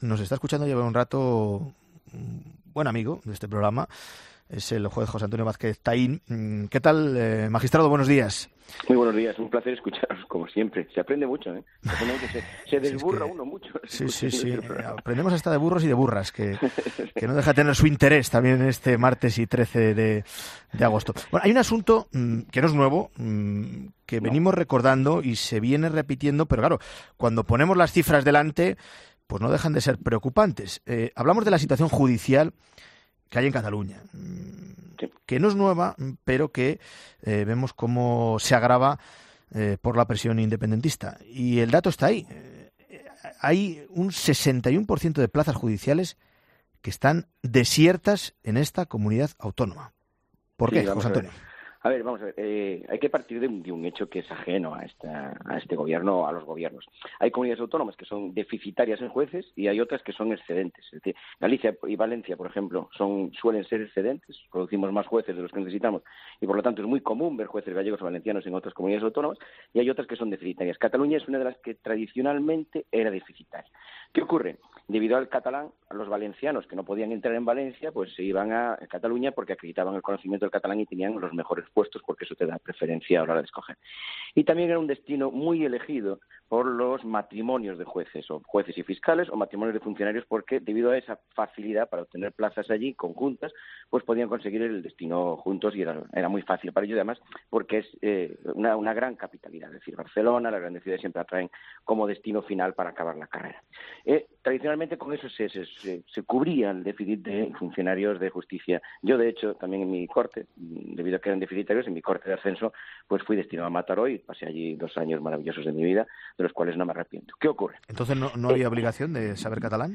El juez José Antonio Vázquez Taín en 'Herrera en COPE'